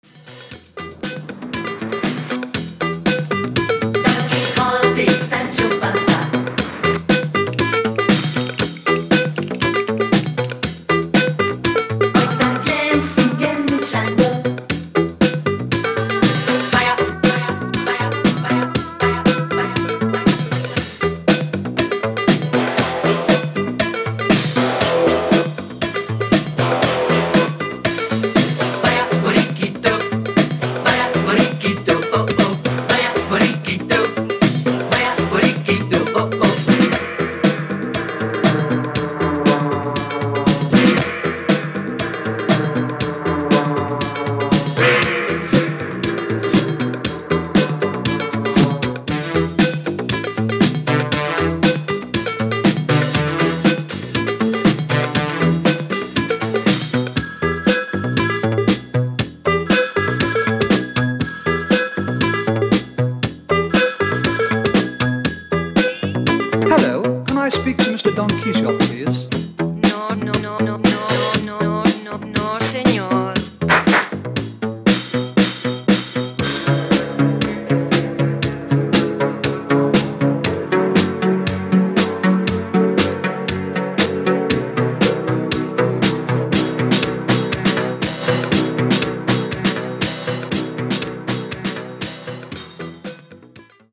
シンセポップ！！！